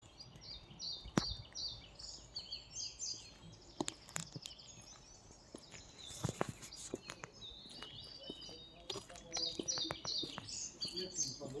Singing birds escorted me into the terrain between winding trails and luscious vegetation. Following the sound of frogs, I came to a beautiful pond.
The sound of arriving at the first interview location
Psychedelics-and-Nature-Arriving-to-location.mp3-.mp3